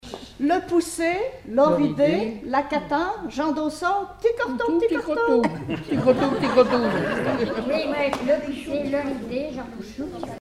Patois local
formulette enfantine : jeu des doigts
Collectif-veillée (1ère prise de son)